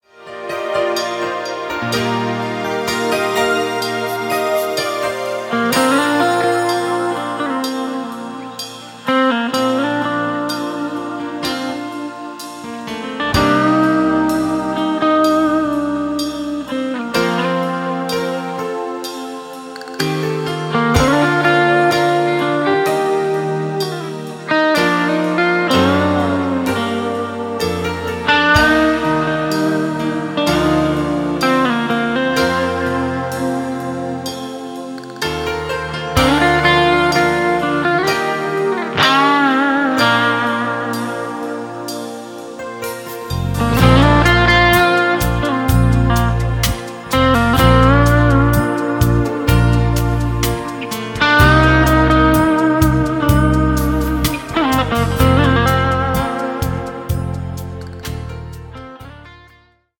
Instrumental
Dabei greift er nicht nur auf die E-Gitarre zurück
akustischen Gitarre